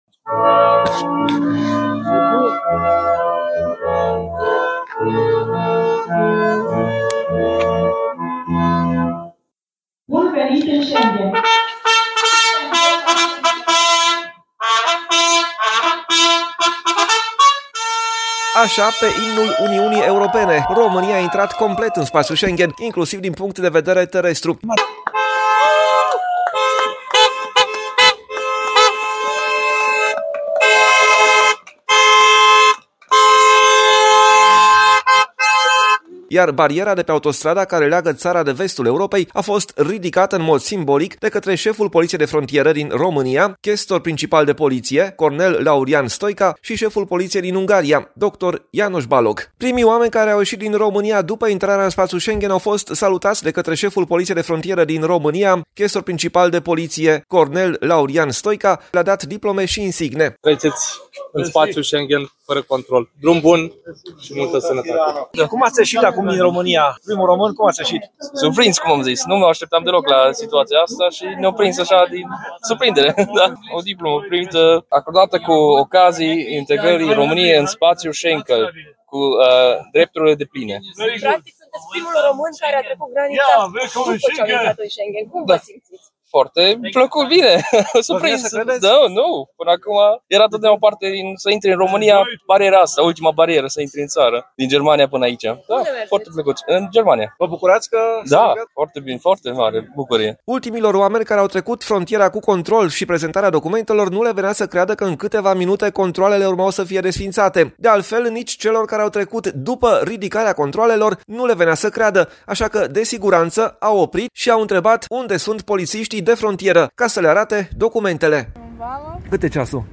În cel mai circulat punct rutier de trecere a frontierei din România, Nădlac 2 de pe autostrada Arad-Nădlac, ceremonia s-a desfășurat in prezența șefilor Poliției de Frontieră din România și Ungaria.
Pe imnul Europei, România a aderat complet la Spațiul Schengen, iar bariera de pe autostrada care leagă țara de vestul Europei a fost ridicată în mod simbolic de cei doi șefi ai polițiilor de frontieră.